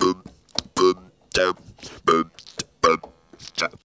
BEATVOICE1.wav